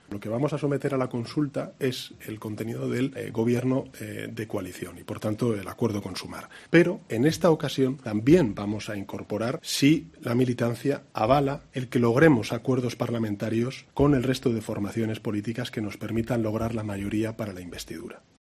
Así lo ha adelantado Sánchez en su comparecencia desde Bruselas tras la reunión de trabajo del Consejo Europeo, donde ha explicado que esta consulta se sumará a la que estaba prevista sobre el acuerdo de gobierno de PSOE y Sumar.